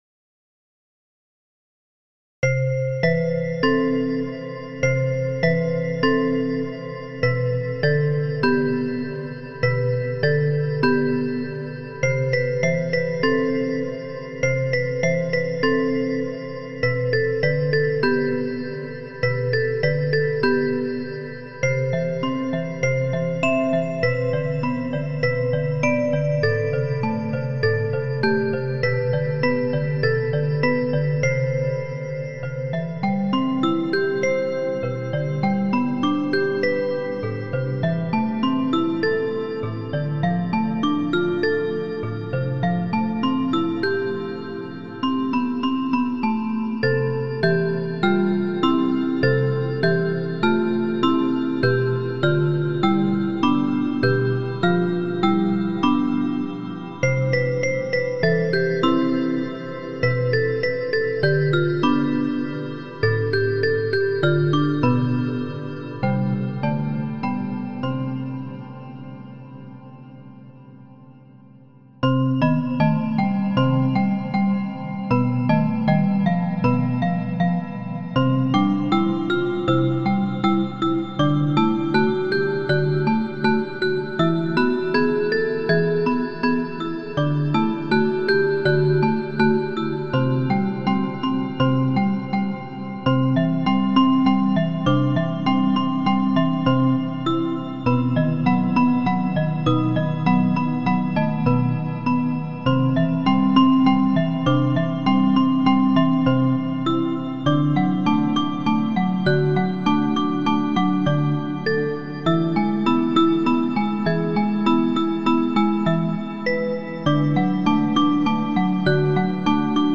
Well, I'm not fond of the orchestral, harmonical mastery here. 1:12 and 1:33 seems to come out of nowhere. I forgive you because you certainly knew the marionettes was something childish, so using children's harmony was the right choice.